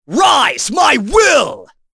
Chase-Vox_Skill6.wav